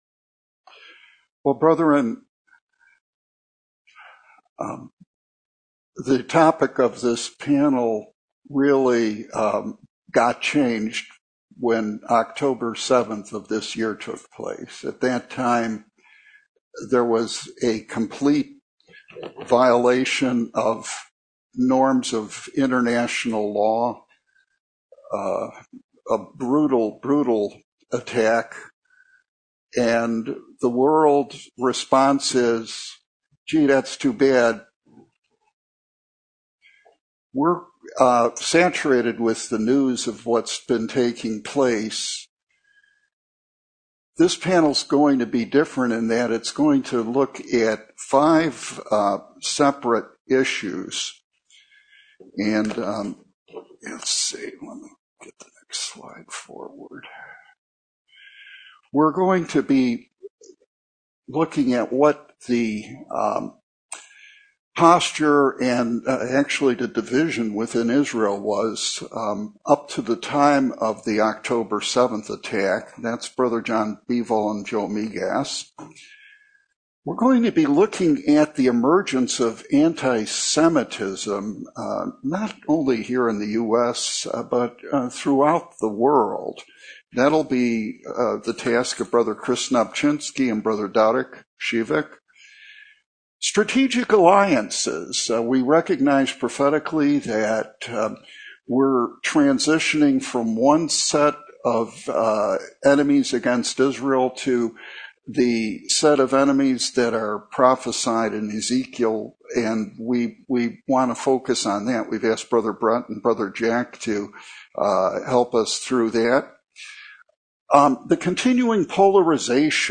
Service Type: Panel Discussion